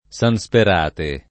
[ S an S per # te ]